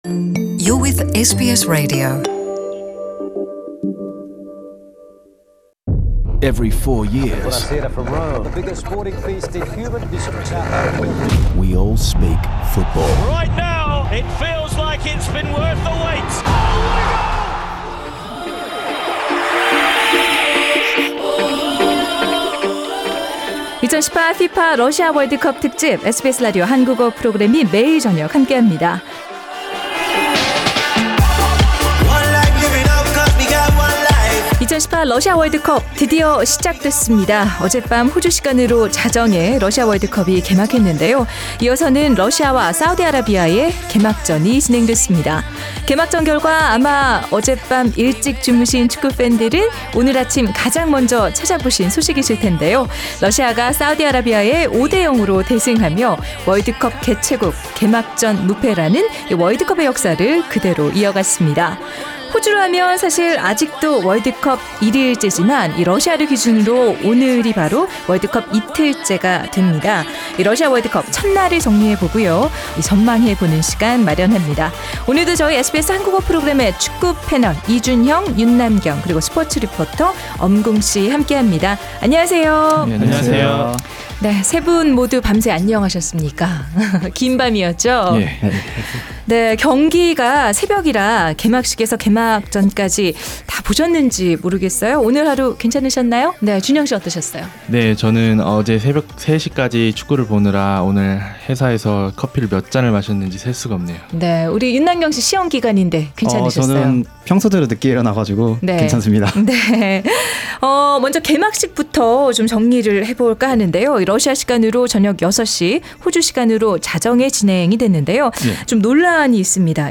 SBS Korean program's Russia World Cup special today examine the opening game. A soccer panel of three in Sydney